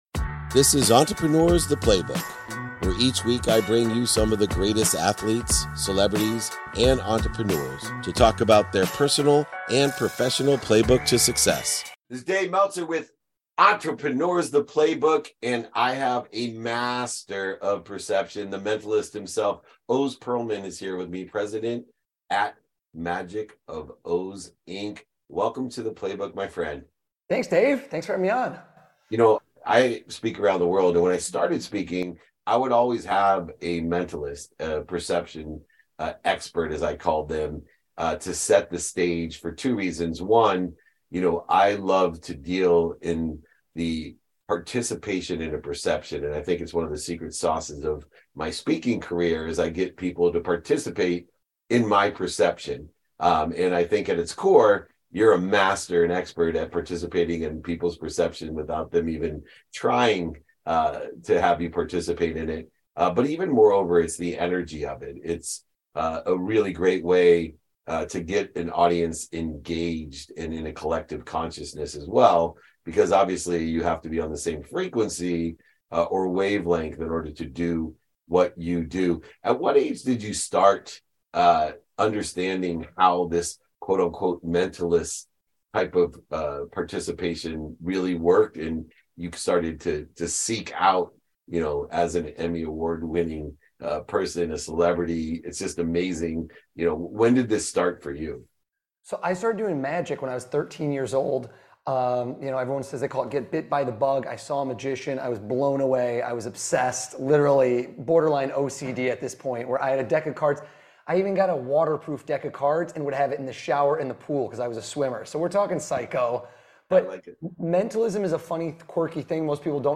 Today's episode revisits a conversation I had with Oz Pearlman, President at Magic of Oz Inc. and a master of mentalism.